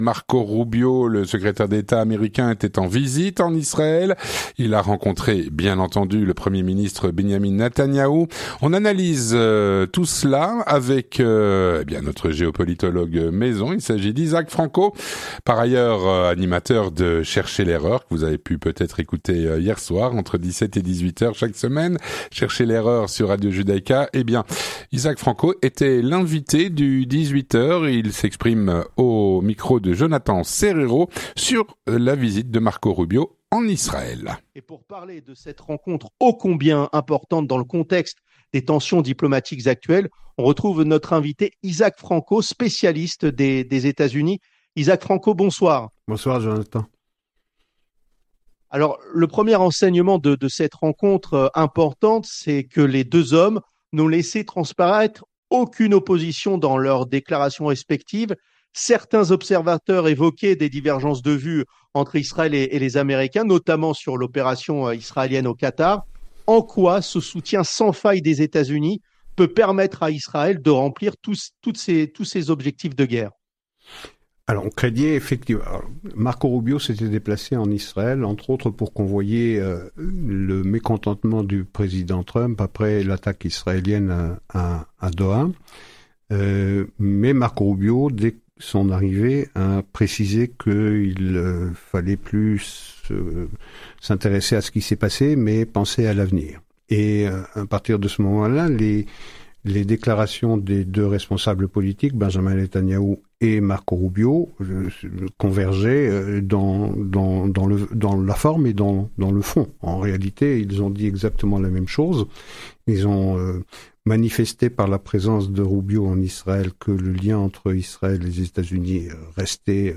L'entretien du 18H - La visite de Marco Rubio en Israël.